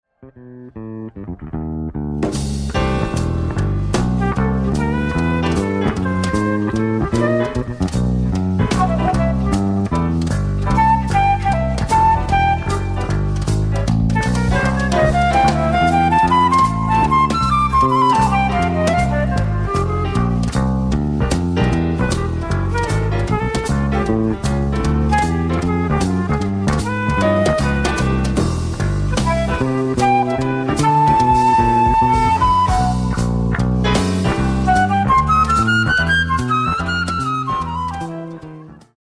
The Best In British Jazz
Recorded at Wave Studios, 25th / 26th September 1983